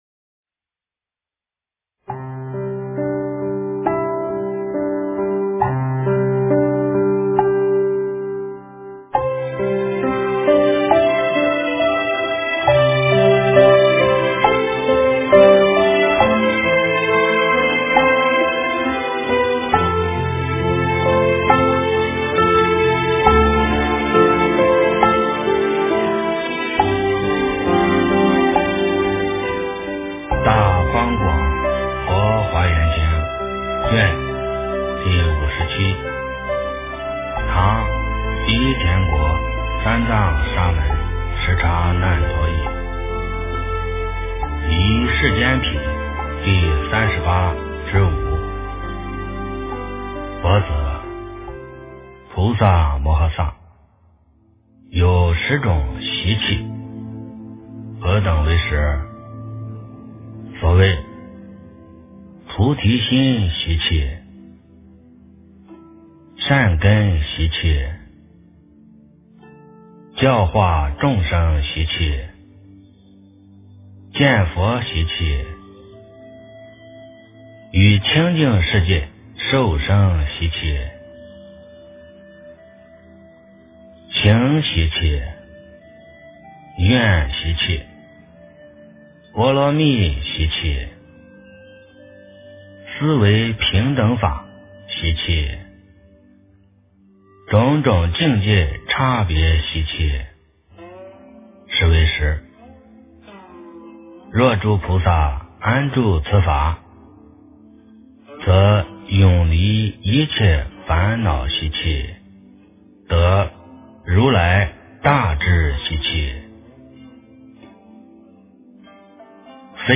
《华严经》57卷 - 诵经 - 云佛论坛